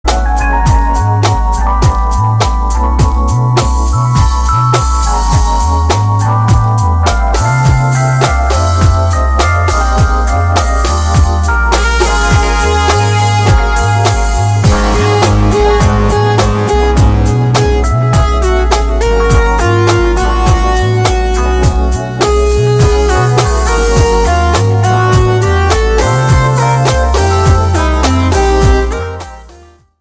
POP  (01.56)